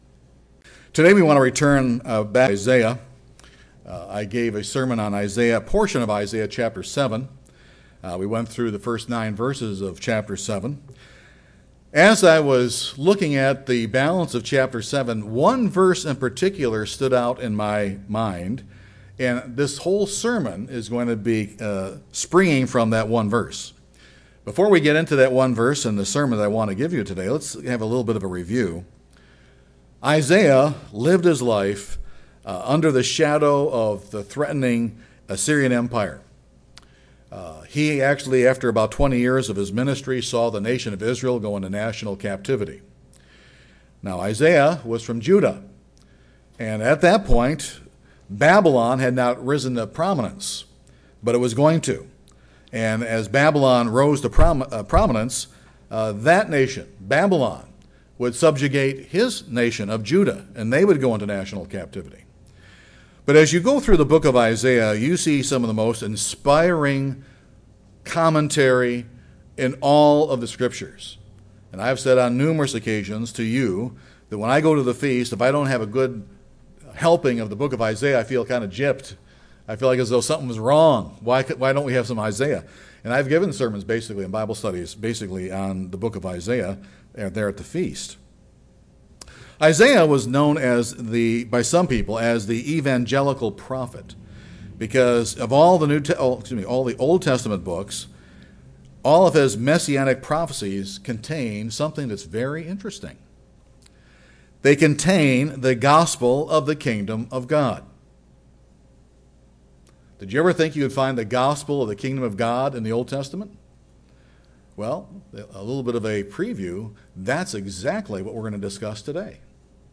This sermon examines how the gospel of the Kingdom of God is taught in the Book of Isaiah.